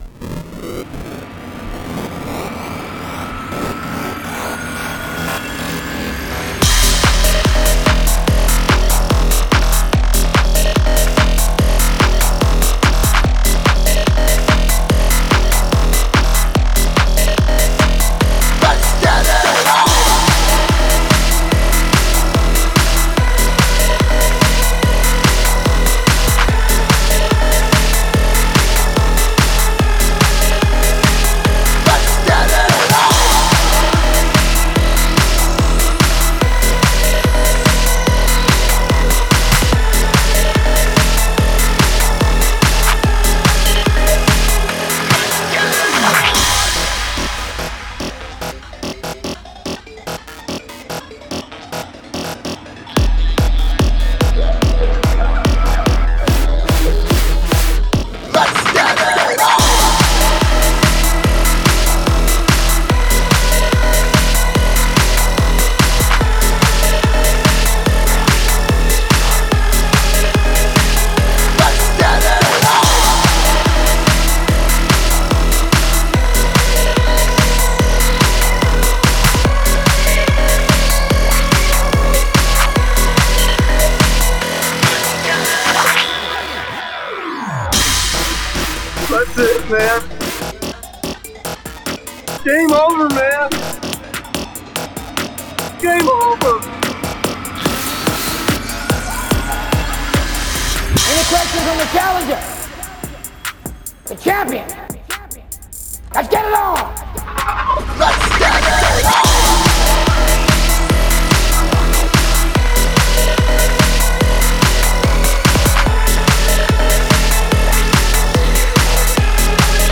stellar and groovy remix
Music / Techno
industrial techno